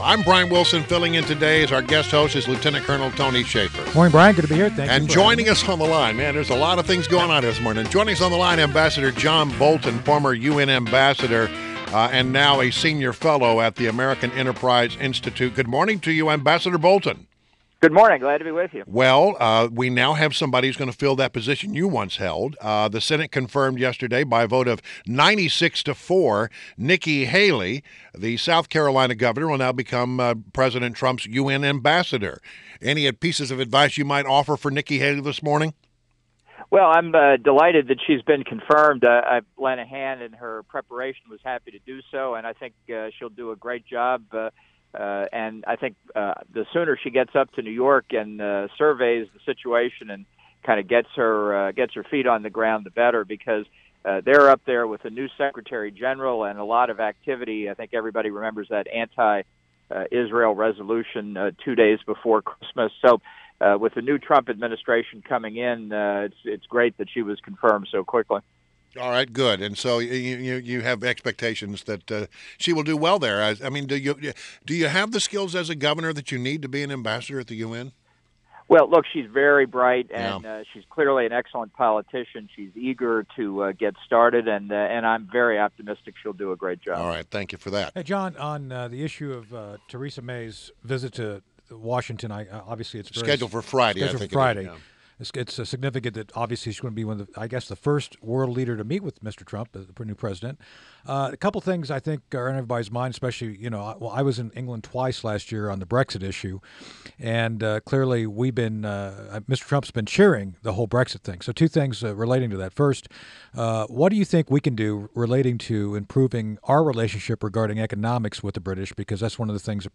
INTERVIEW – AMB. JOHN BOLTON – former U.S. ambassador to the United Nations and a Senior Fellow for the American Enterprise Institute